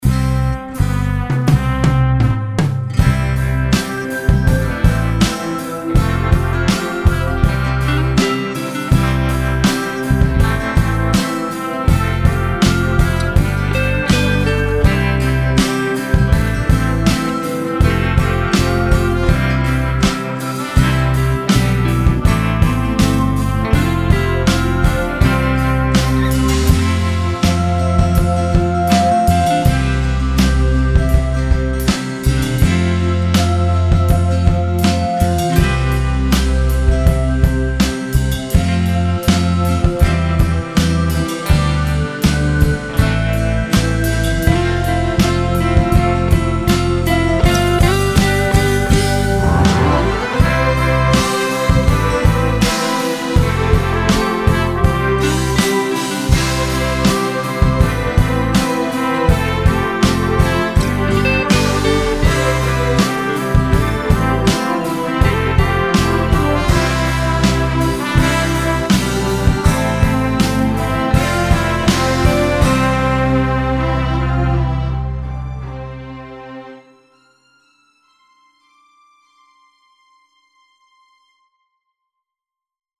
Musikbakgrund